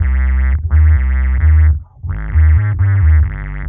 Index of /musicradar/dub-designer-samples/130bpm/Bass
DD_JBassFX_130C.wav